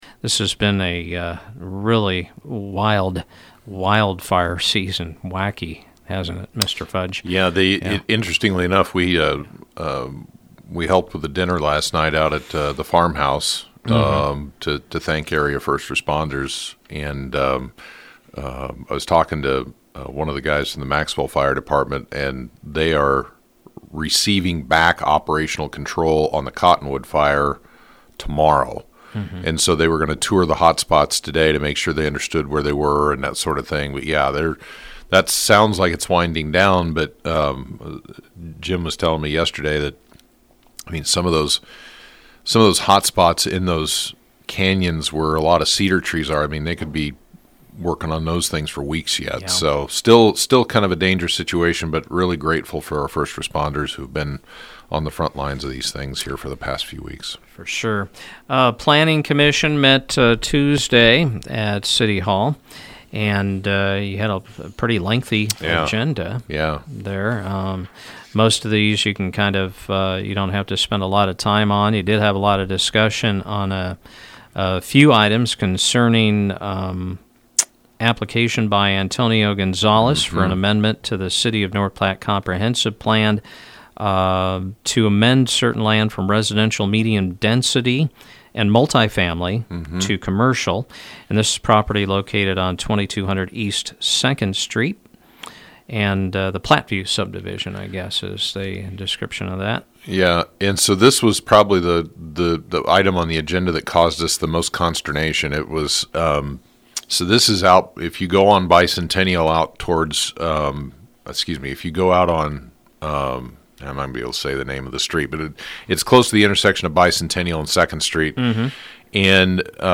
Head of the North Platte Planning Commission, David Fudge, came on Mugs Friday to review last week’s agenda and decisions: